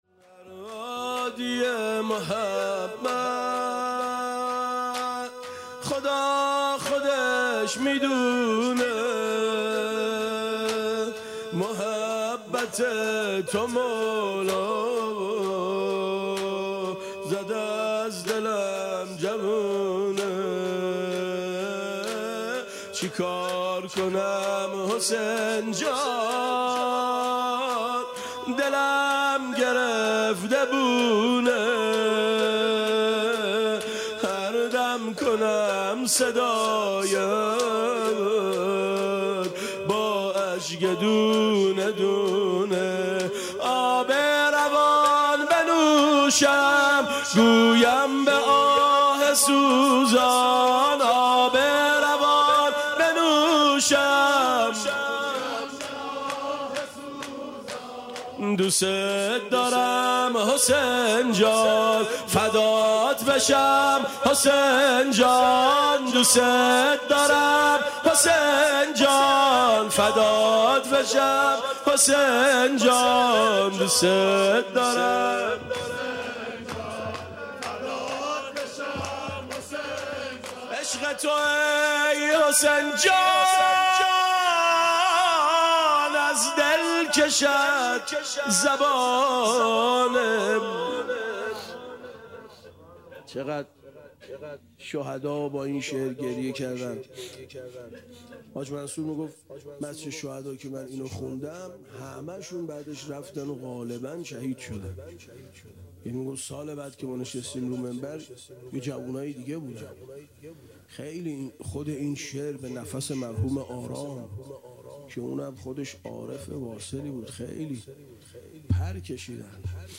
شب ۲۲ م ماه رمضان/ ۳ اردیبهشت ۴۰۱ ماه رمضان دم مداحی اشتراک برای ارسال نظر وارد شوید و یا ثبت نام کنید .